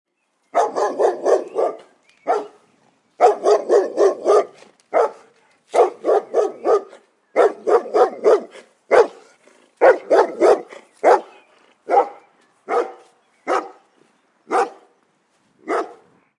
Download Dogs Barking sound effect for free.
Dogs Barking